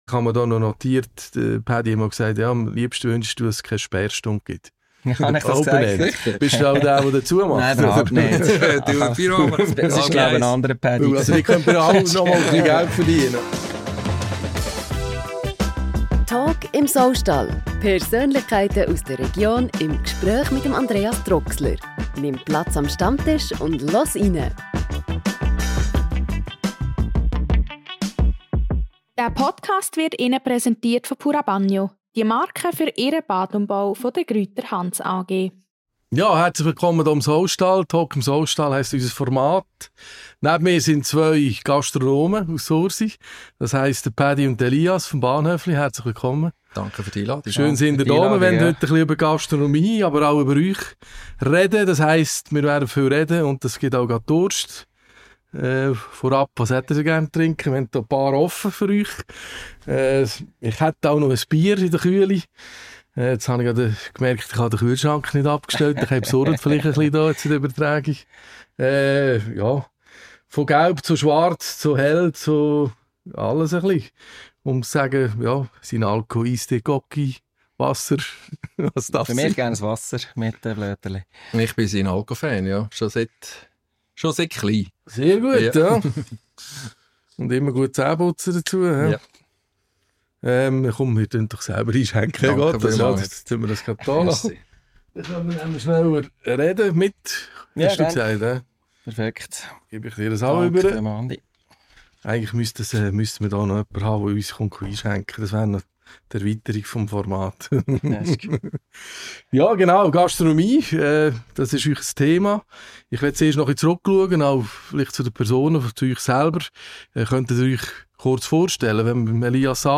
Beschreibung vor 5 Monaten Im monatlichen Podcast im ehemaligen Saustall in Nottwil erfährst du mehr über die Menschen aus der Region.